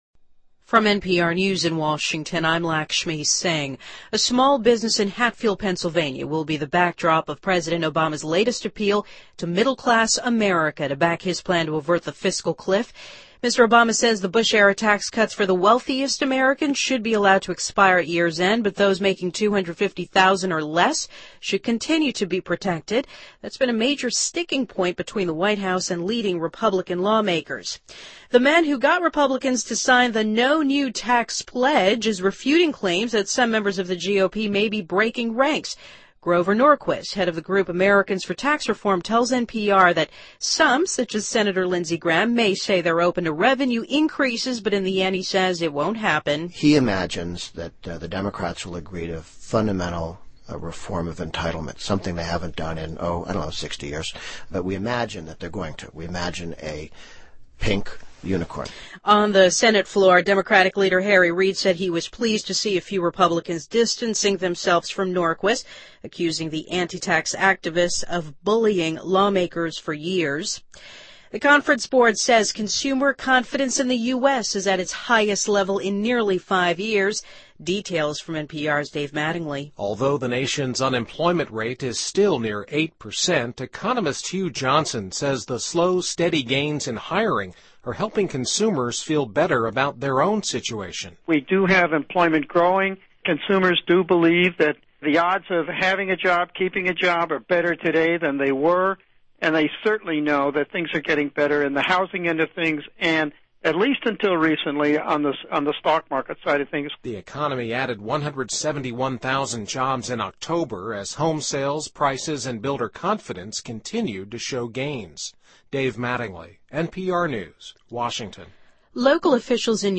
NPR News,2012-11-28